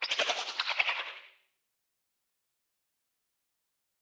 Creature_Sounds-Spider_1-002.ogg